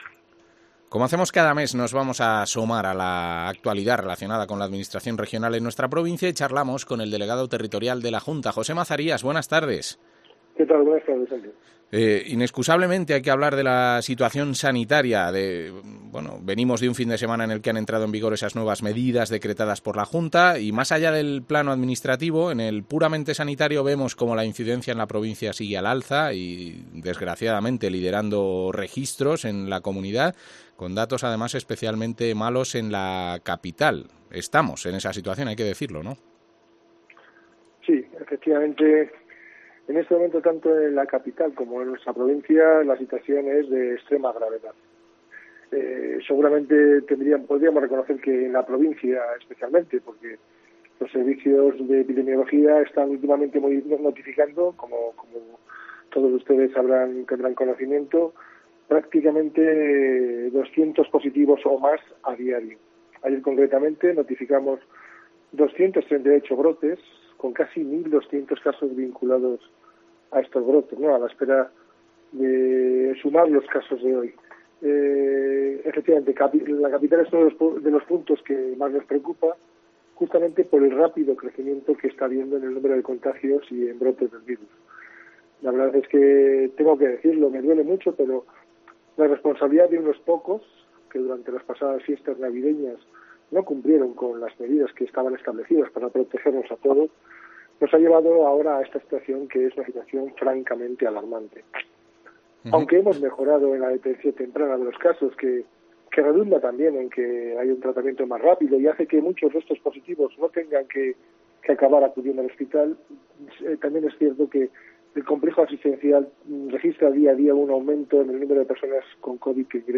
Entrevista al delegado territorial de la Junta, José Mazarías